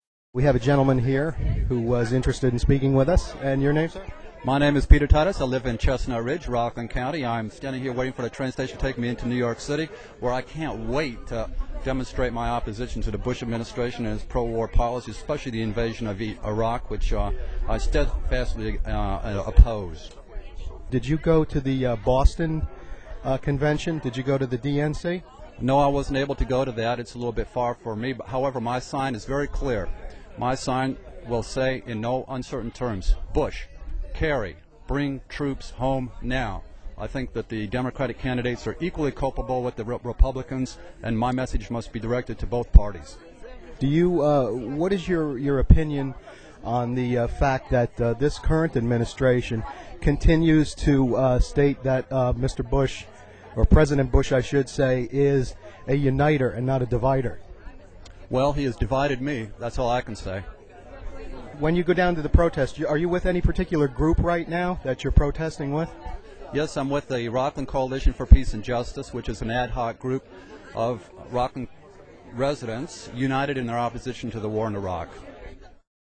The interviews are presented here in roughly chronological order, starting with audio from the major protest march on Sunday, August 29, the day before the convention officially started.